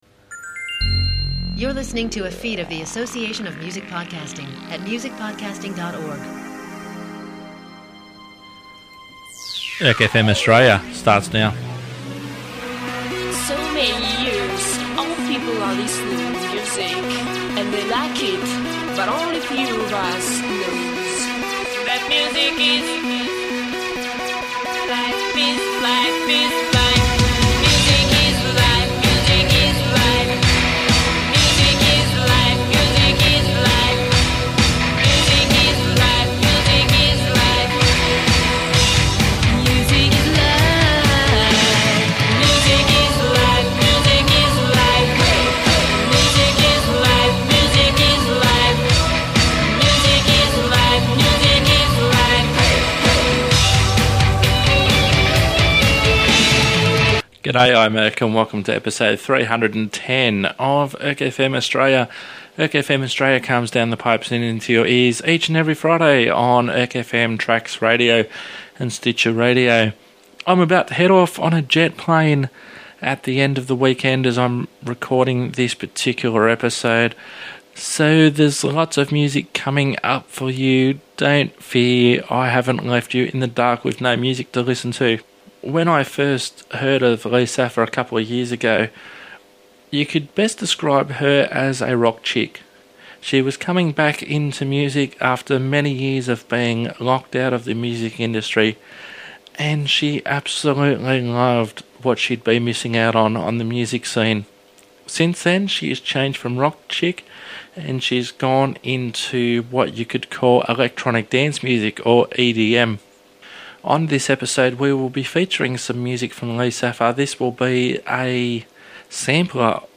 She has changed focus and now produces electronic dance music (EDM).